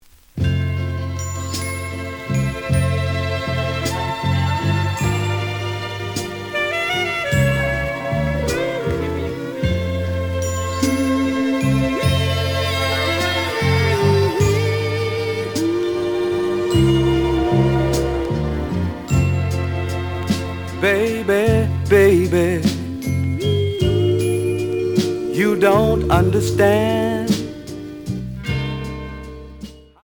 The audio sample is recorded from the actual item.